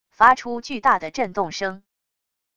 发出巨大的震动声wav音频